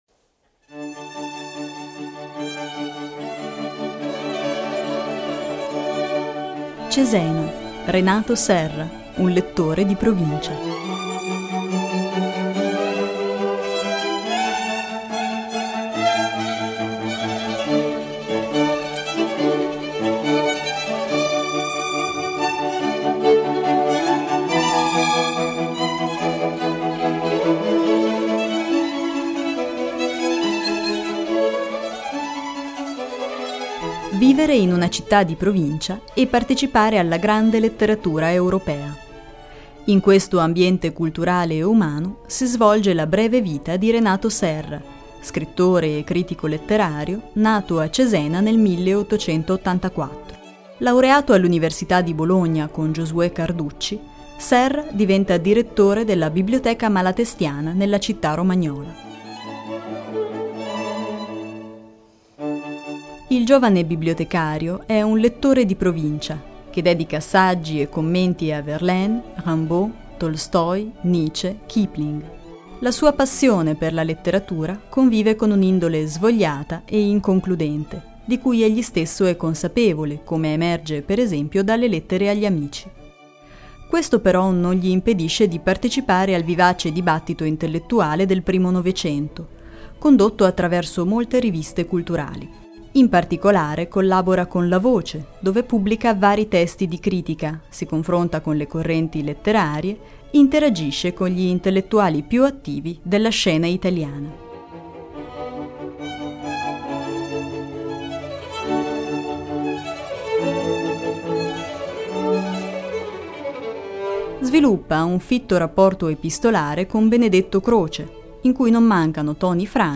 Ascolta la lettura della scheda (mp3, 7319 kB)